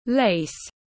Vải ren tiếng anh gọi là lace, phiên âm tiếng anh đọc là /leɪs/.